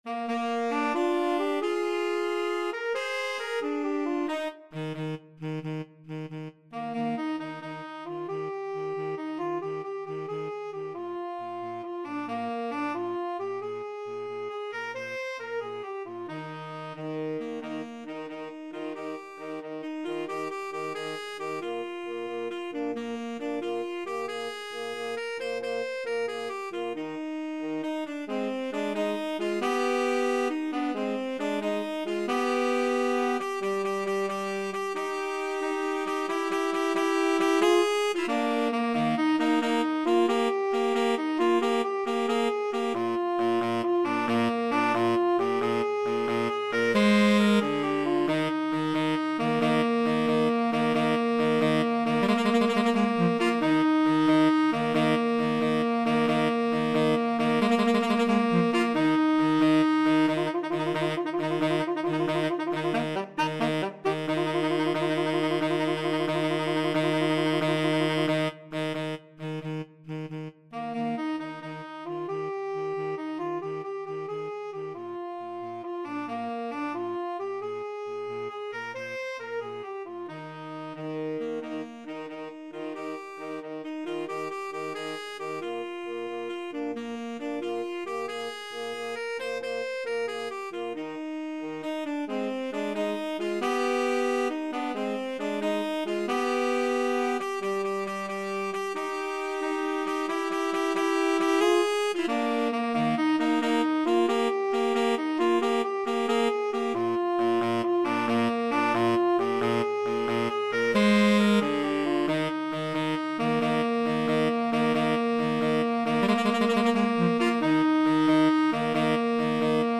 Free Sheet music for Tenor Sax Duet
Eb major (Sounding Pitch) (View more Eb major Music for Tenor Sax Duet )
6/8 (View more 6/8 Music)
Rollicking . = c. 90